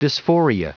Prononciation du mot dysphoria en anglais (fichier audio)